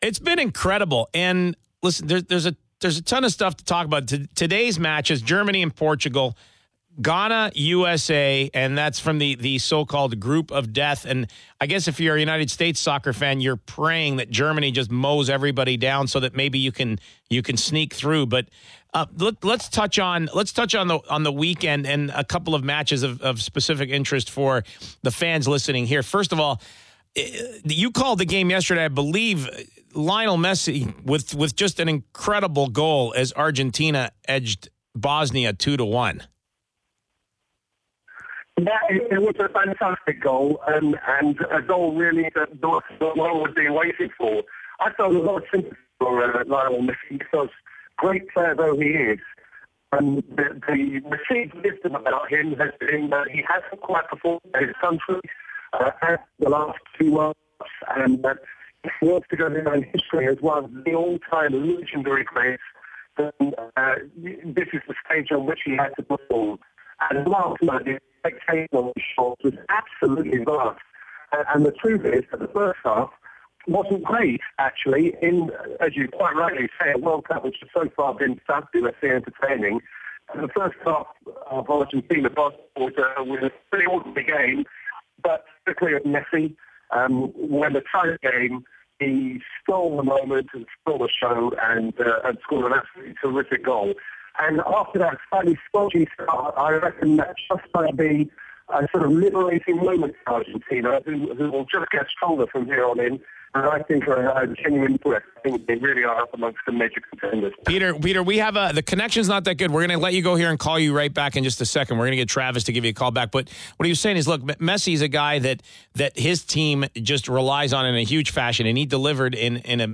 Peter Drury, one of the play-by-play commentators on the international television feed, joined Sportsnet 590 The FAN to discuss his thoughts on the World Cup.